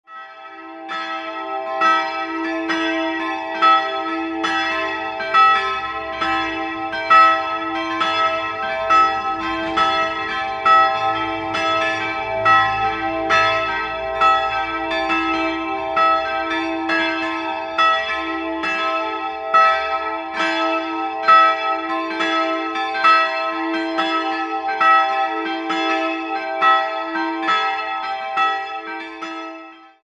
Jahrhundert versehen sind. 3-stimmiges TeDeum-Geläute: e''-g''-a'' Die drei Glocken stammen von 1959 aus der Gießerei F.W.Schilling und wiegen 168, 95 und 71 kg.